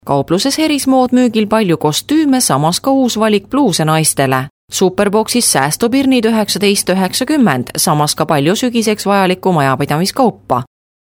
Sprecherin estnisch (Muttersprache) für Werbung, Voice over, Imagefilm, Industriefilm etc.
Sprechprobe: Industrie (Muttersprache):
Professionell female estonian voice over artist